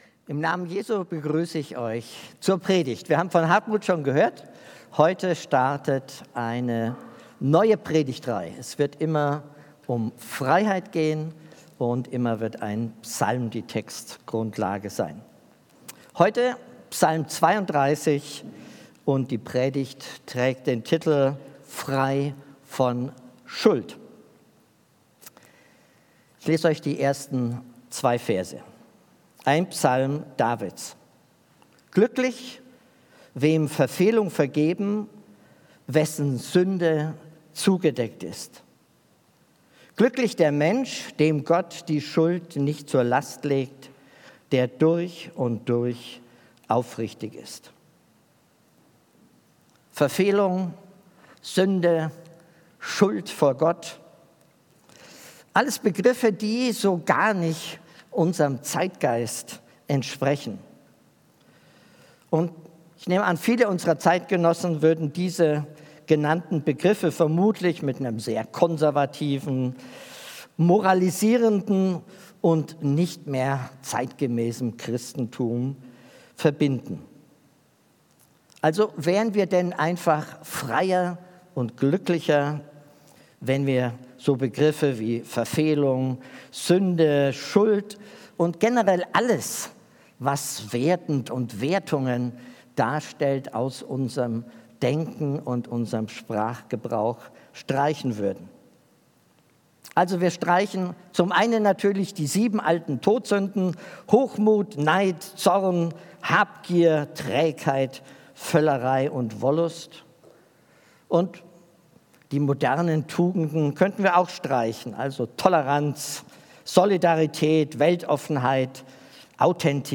Gottesdienst
Predigt